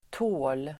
Uttal: [tå:l]